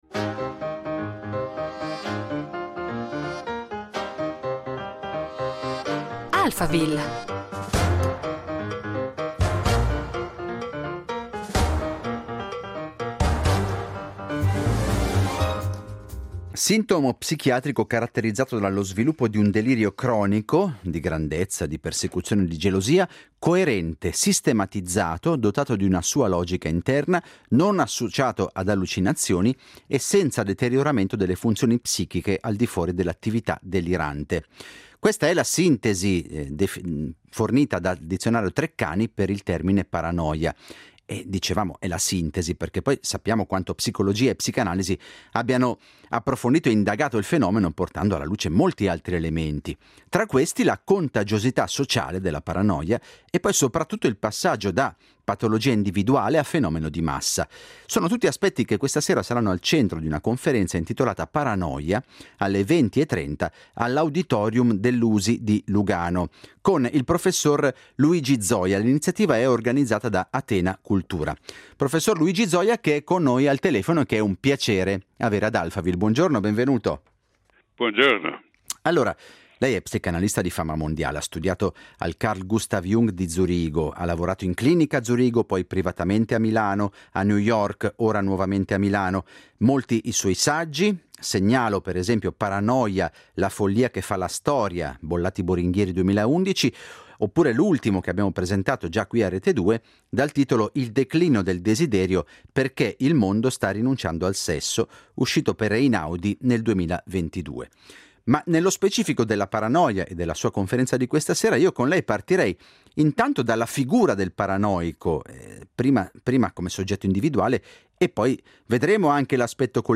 Luigi Zoja è ospite di Alphaville , in occasione della conferenza che tiene stasera all’USI proprio sulla paranoia.